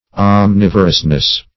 Definition of omnivorousness.